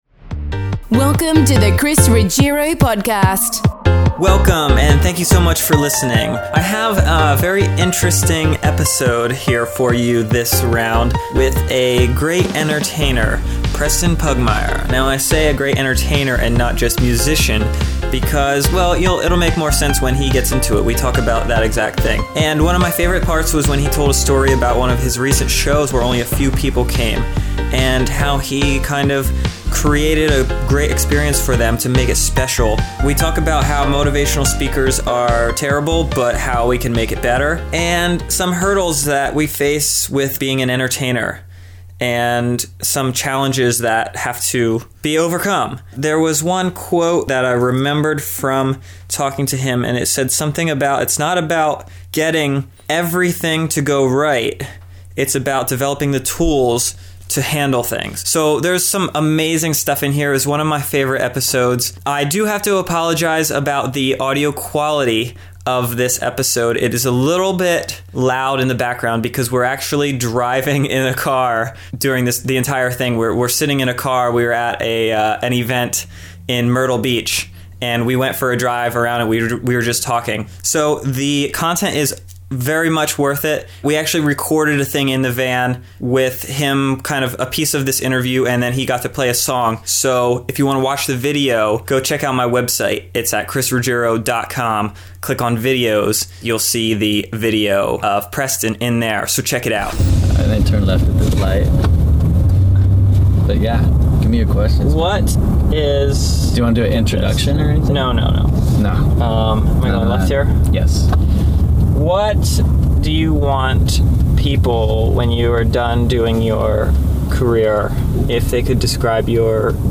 An interesting and inspiring talk with musician